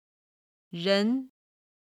ただ軽音部分の音源がないので、元々の単語の四声にしています。
音源には本来の四声の数字を表記(軽声は5と表記)、音と目で音源の四声が分かるようにしています。